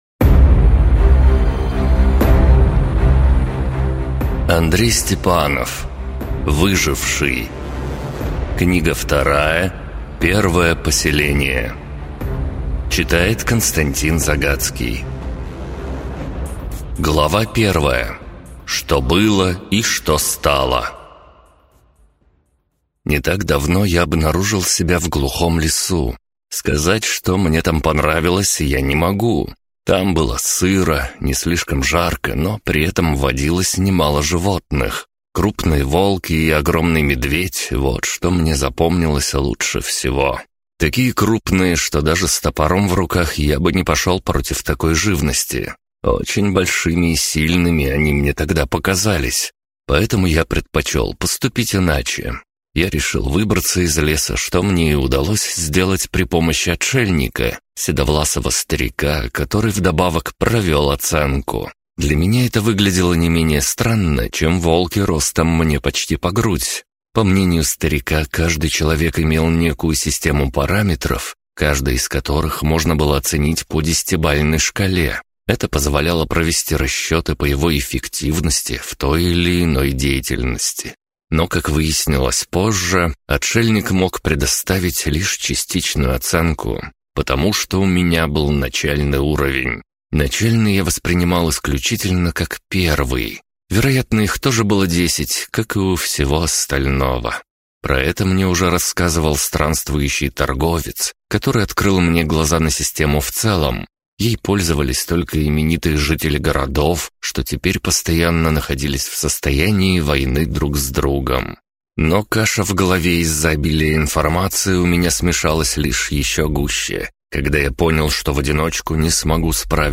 Аудиокнига Выживший: Первое поселение | Библиотека аудиокниг